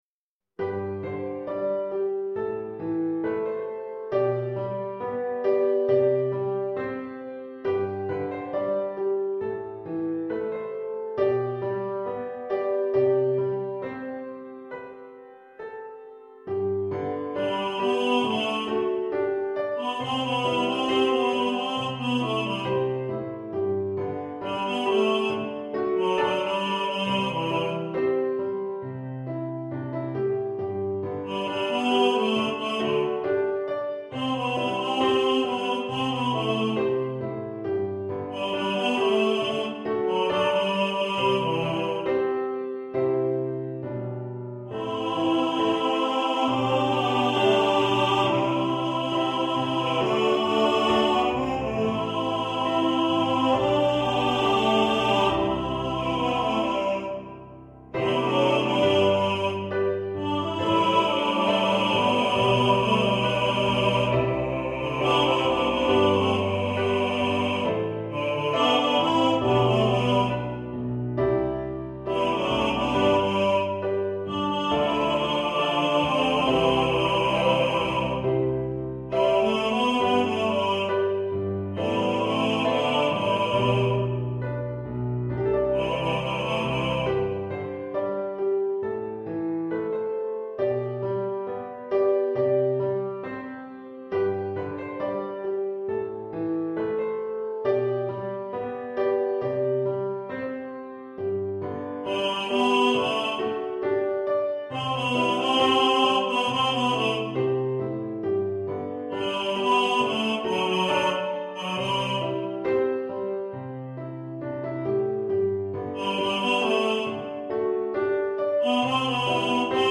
Als Chorsatz hier in der dreistimmigen Version.